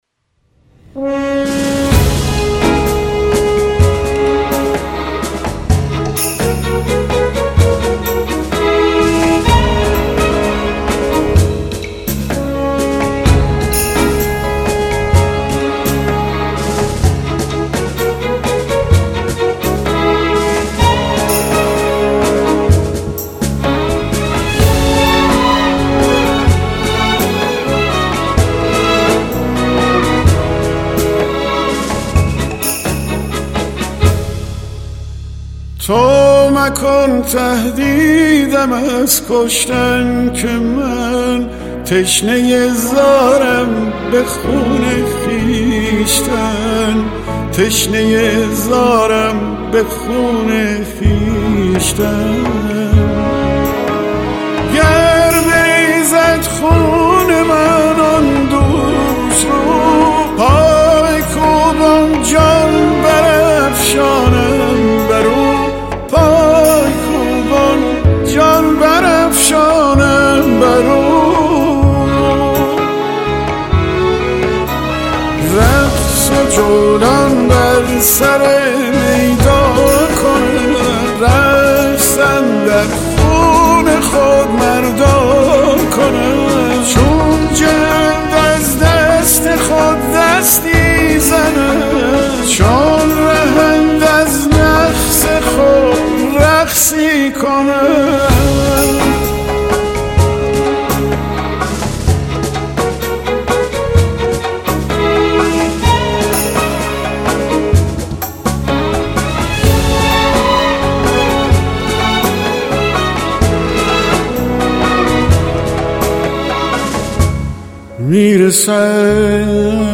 ترانه